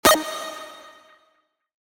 Classic One Shot 10 D#
Classic-One-Shot-10-D.mp3